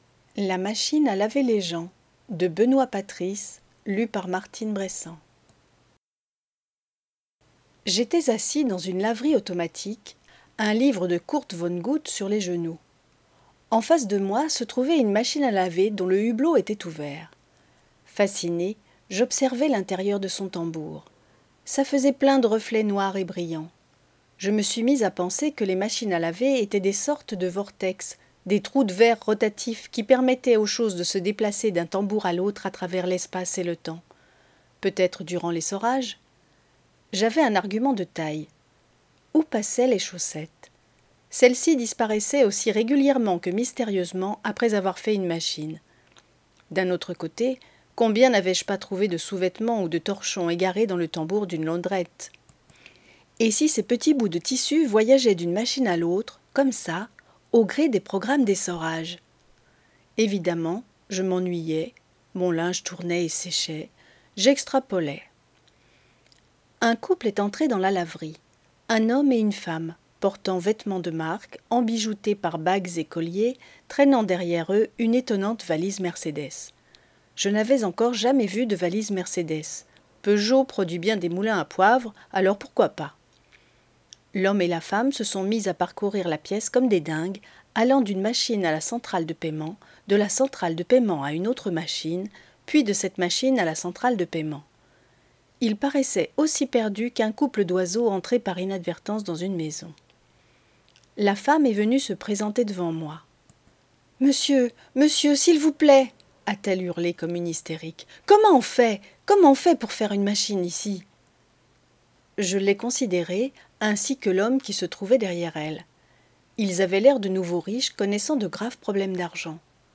NOUVELLE